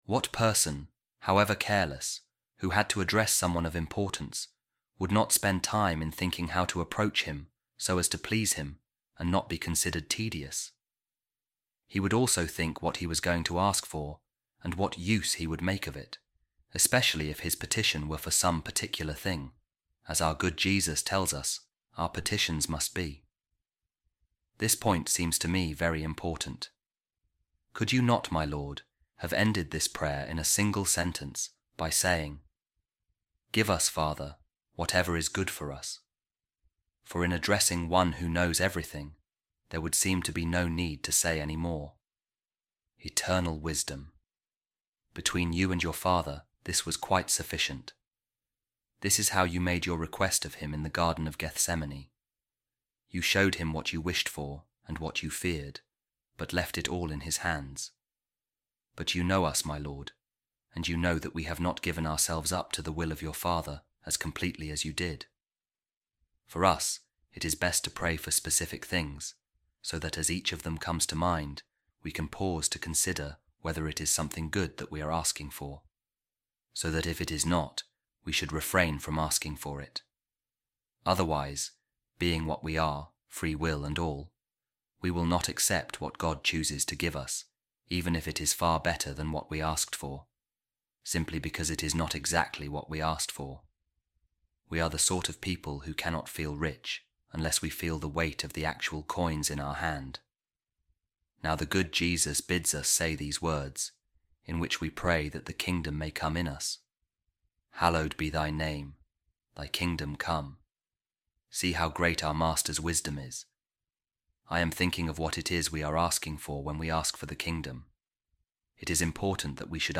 A Reading From The Way Of Perfection By Saint Teresa Of Avila | Thy Kingdom Come